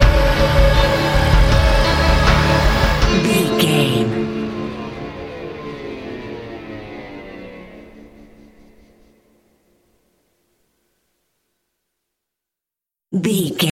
Thriller
Aeolian/Minor
D
Slow
drum machine
synthesiser
electric piano
electric guitar
ominous
dark
suspense
haunting
creepy